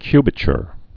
(kybə-chr, -chər)